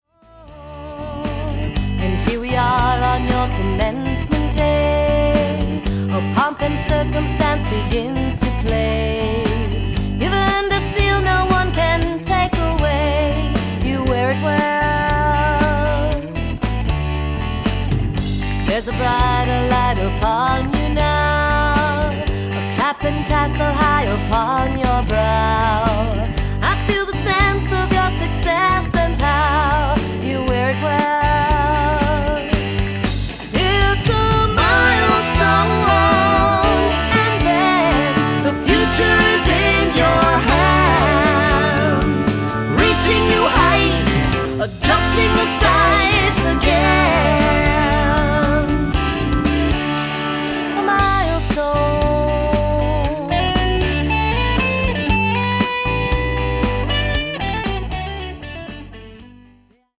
Vocals
Guitar, bass, organ, and drums